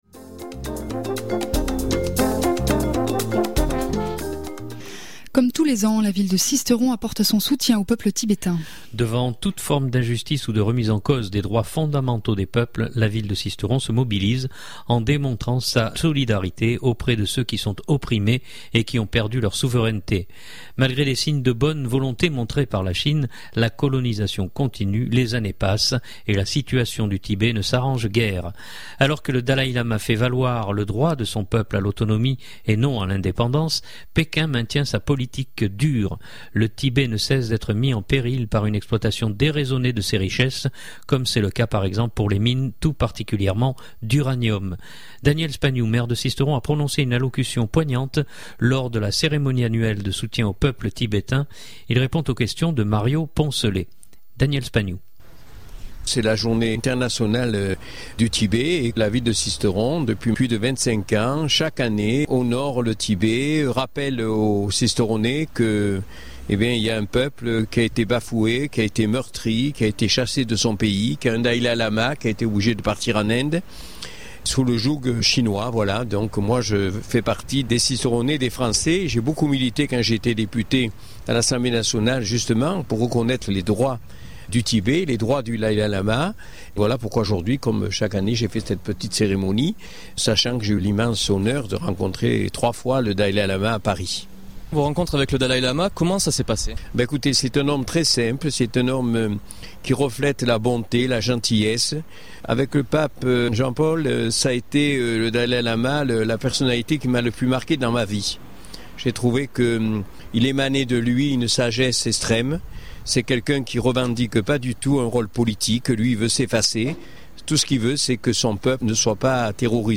Daniel Spagnou, Maire de Sisteron, a prononcé une allocution poignante lors de la cérémonie annuelle de soutien au peuple Tibétain.